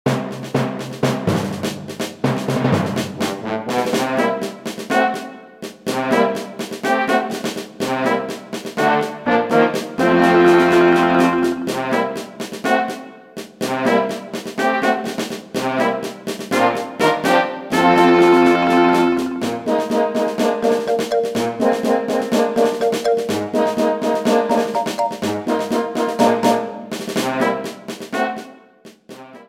Ripped from the game files
applied fade-out on last two seconds when needed